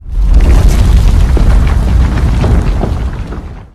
quake.wav